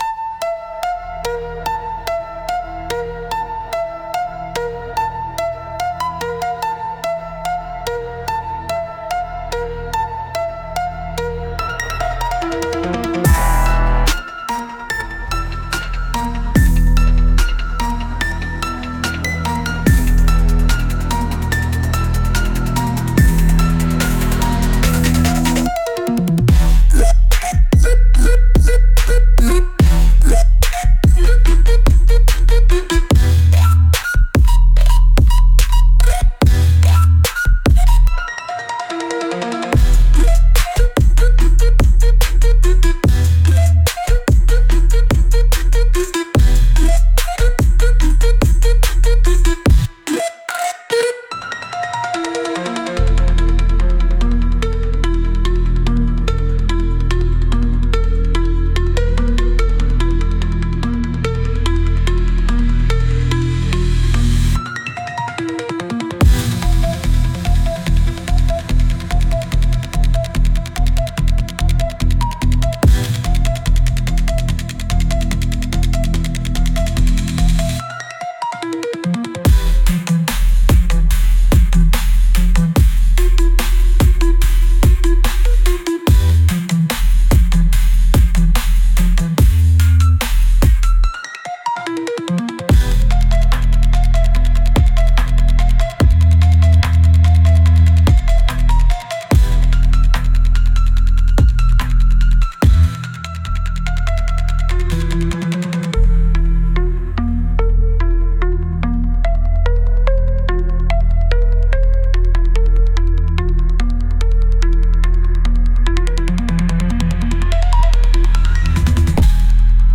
Genre: Trap Mood: Gritty Editor's Choice